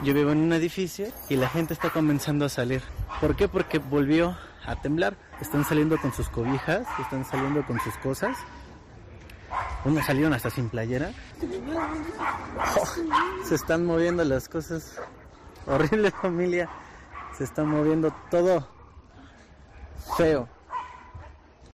Testigo del último terremoto en México: "Hemos salido hasta sin playeras, se está moviendo todo, es feo"
La tierra ha vuelto a moverse de forma violenta provocando que la gente tuviera que salir de sus casas como ha contado a COPE un testigo: "hemos salido hasta sin playeras, se está moviendo todo, es feo".